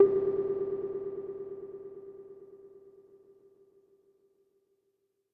boop